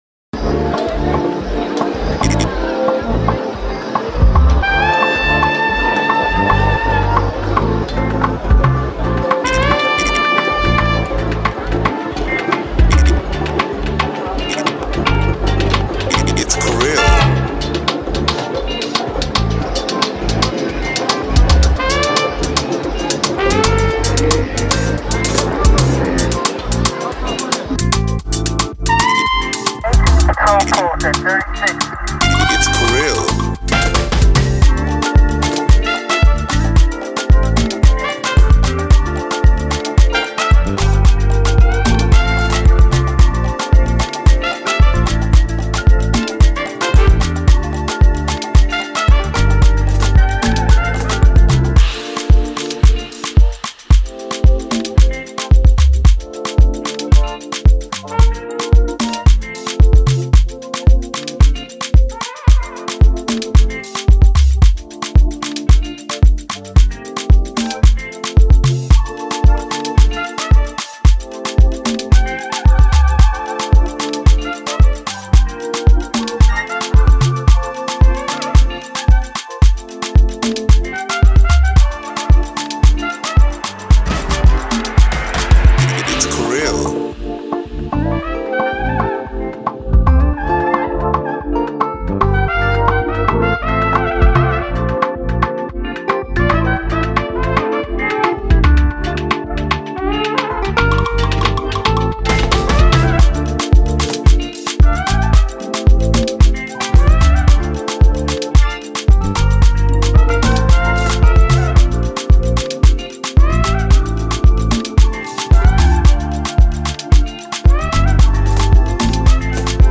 March 12, 2025 Publisher 01 Gospel 0